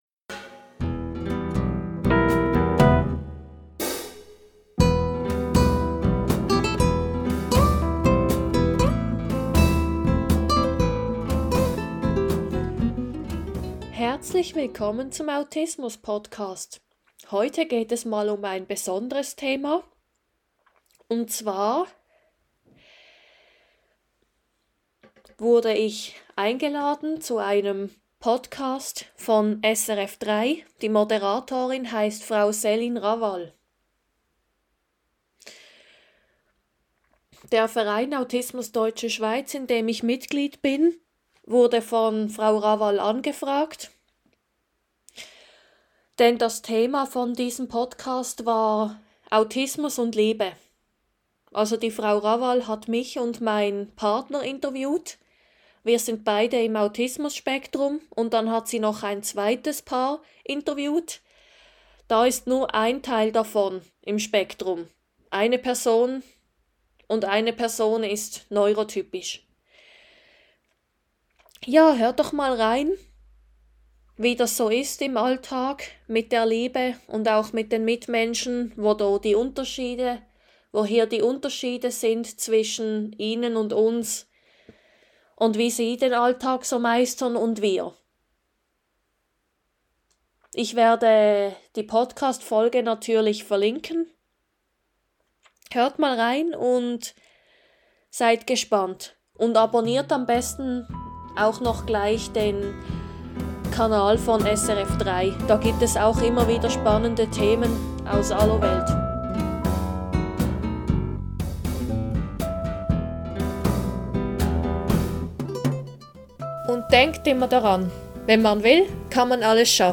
Interview SRF 3
Interview_SRF_3.mp3